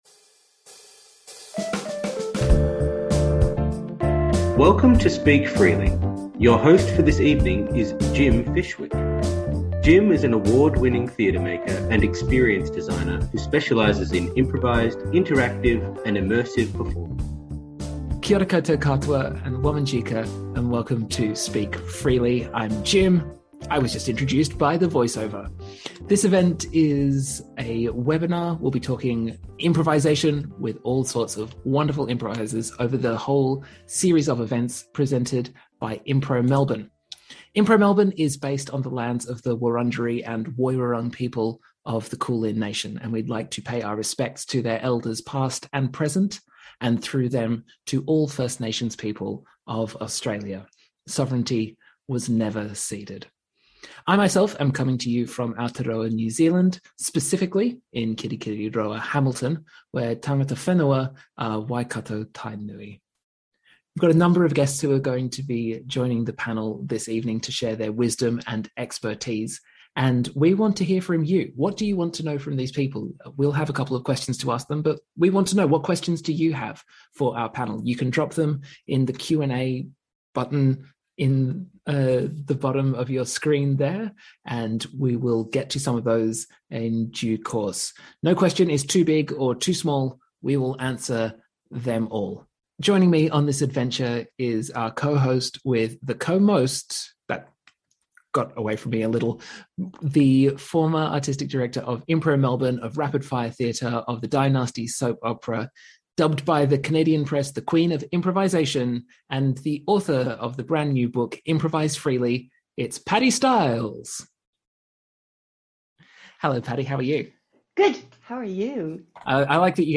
Recorded live on zoom in September 2021, this is the audio only version of the first 'Speak Freely' Webinar, presented by Impro Melbourne.